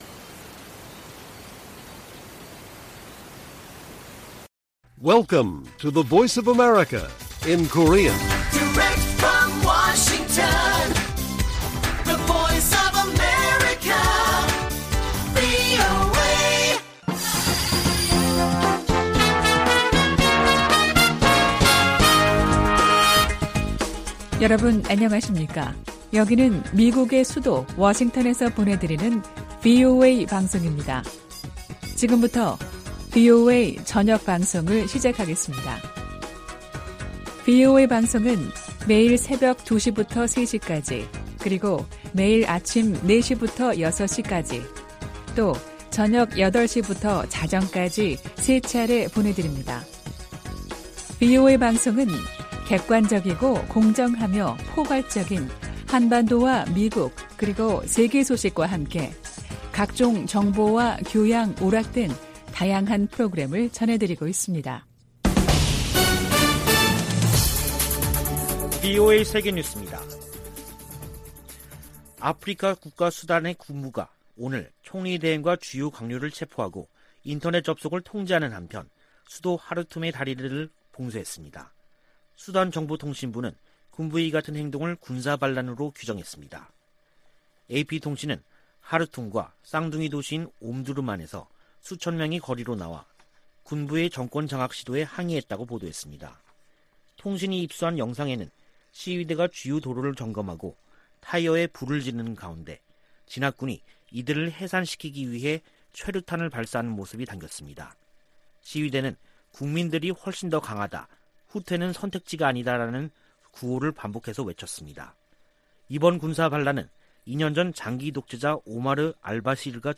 VOA 한국어 간판 뉴스 프로그램 '뉴스 투데이', 2021년 10월 25일 1부 방송입니다. 성 김 미 대북특별대표는 한반도 종전선언 등 관여 방안을 계속 모색하겠다면서도 북한의 탄도미사일 발사를 도발이라고 비판했습니다. 제76차 유엔총회에 북한 핵과 탄도미사일 관련 내용이 포함된 결의안 3건이 발의됐습니다. 북한이 플루토늄 추출과 우라늄 농축 등 핵 활동을 활발히 벌이고 있다는 우려가 이어지고 있습니다.